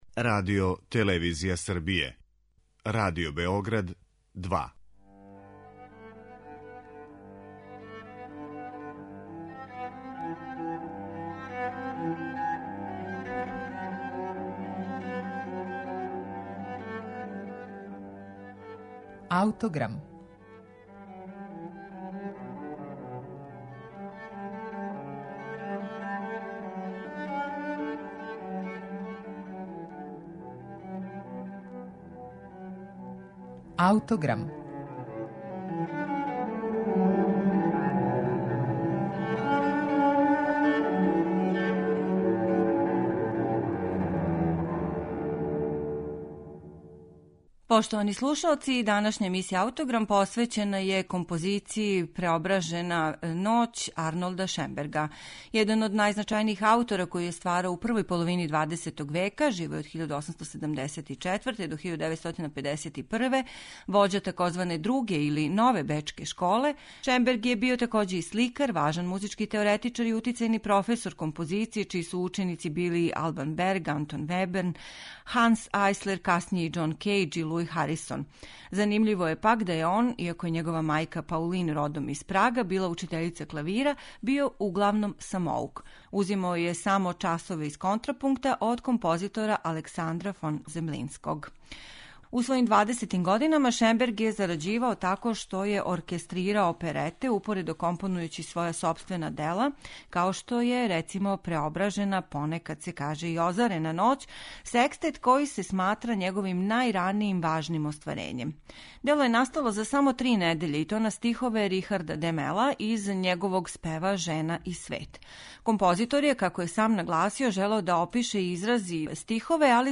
Шенбергов секстет 'Преображена ноћ'